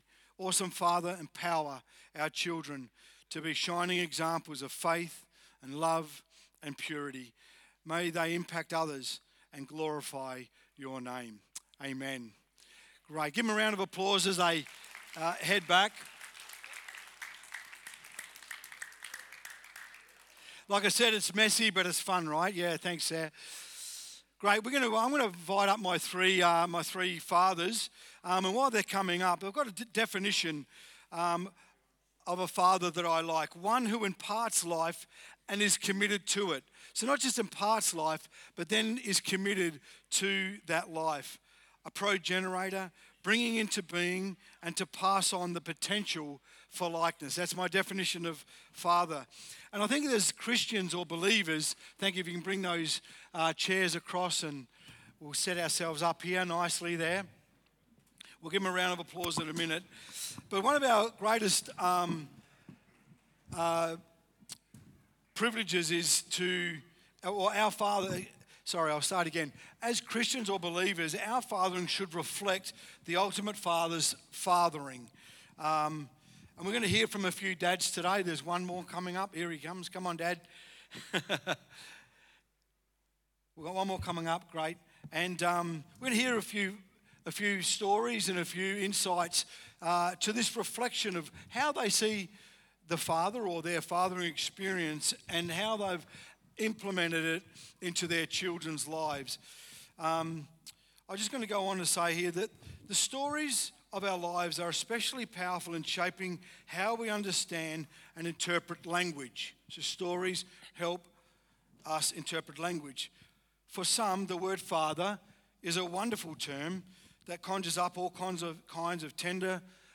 This morning is a special service for Father’s Day featuring heartfelt messages from our kids and wisdom from three Dads as they share their journeys in fatherhood.